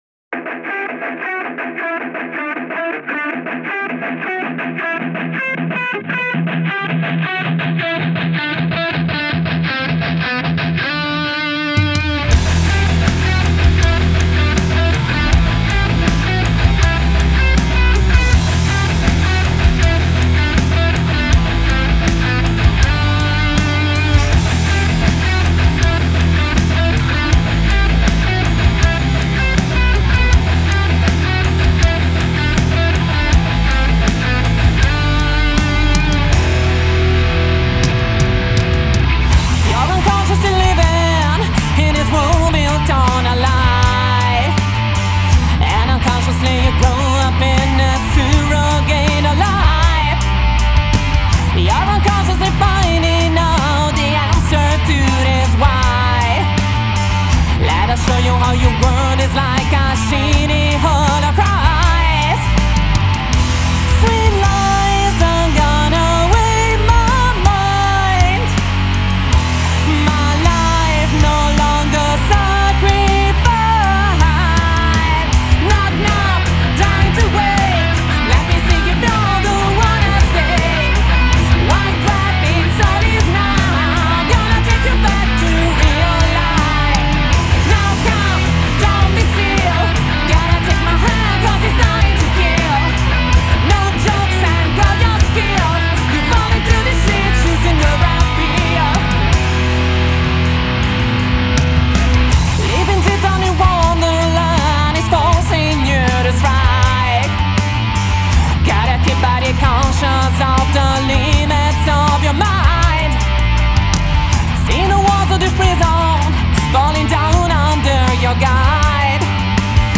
hard rock and power metal